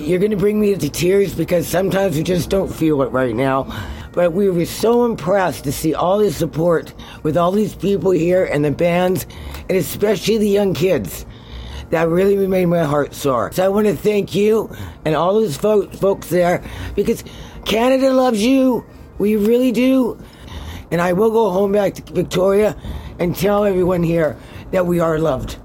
Port Angeles – Despite the cold, the mood was warm and festive Thursday afternoon at the Coho Ferry terminal where hundreds of people showed up with signs and banners welcoming the ferry and its Canadian passengers back to Port Angeles after the boat’s annual winter break.